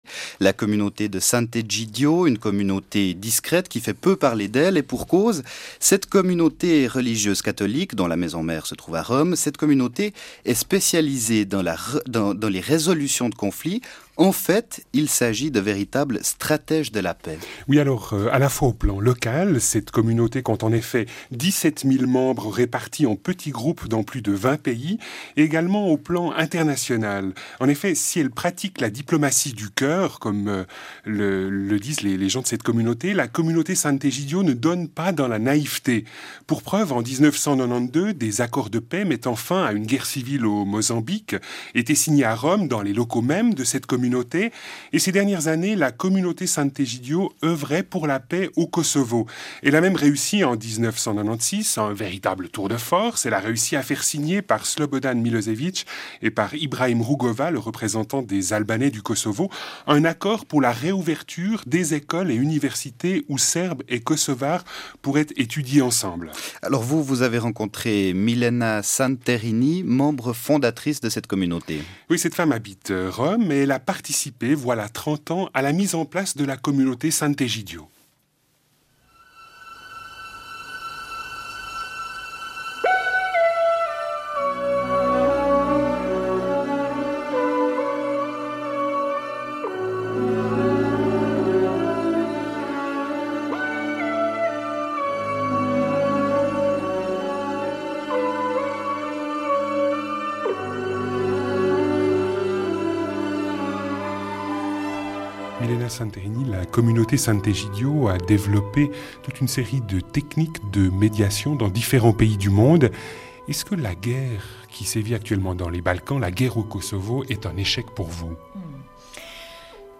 Interview de Milena Santerini, de la Communauté de S. Egidio, dans le magazine radio “Hautes fréquences” (RSR – La Première) diffusé le 25 avril 1999.